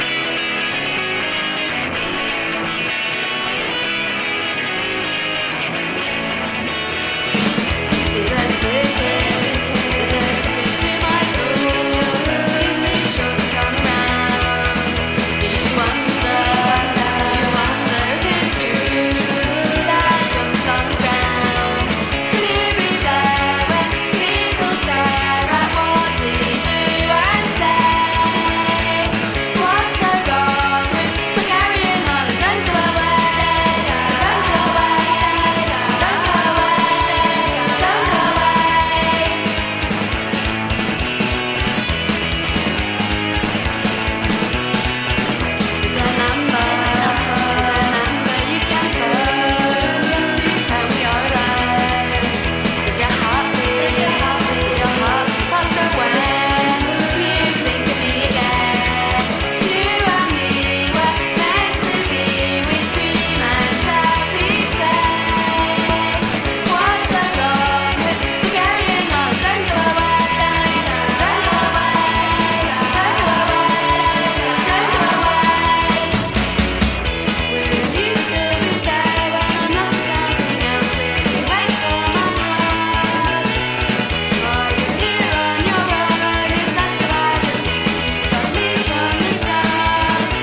レジェンド・オブ・アノラック/TWEEポップ！
冒頭のかき鳴らしギターから胸が熱くなる疾走ジャングリー・ポップ